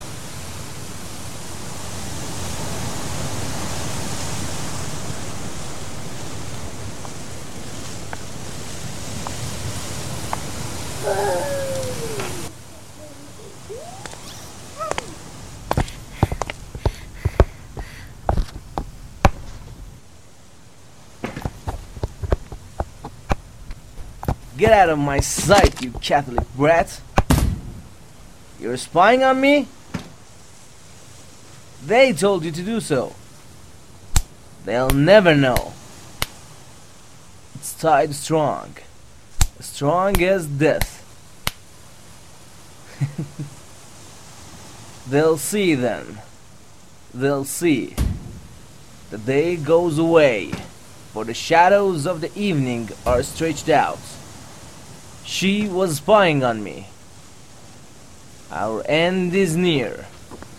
کتاب صوتی انگلیسی The Rope | مرجع دانلود زبان